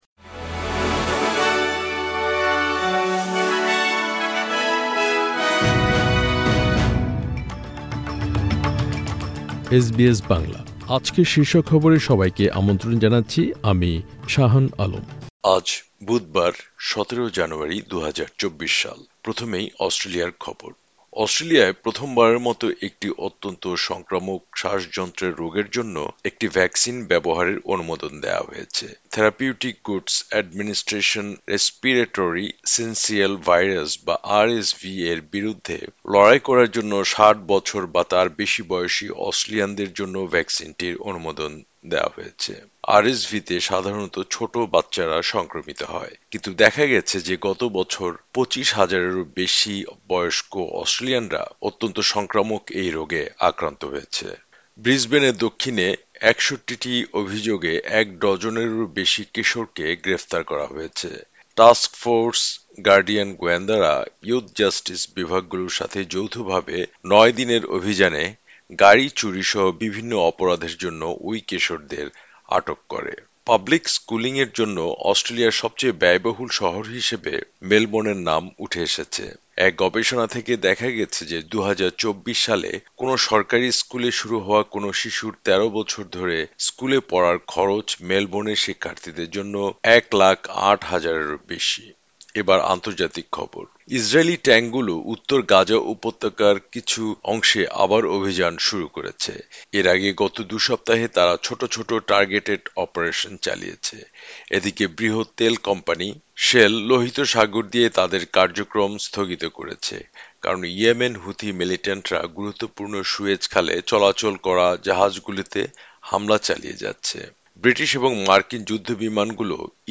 এসবিএস বাংলা শীর্ষ খবর: ১৭ জানুয়ারি, ২০২৪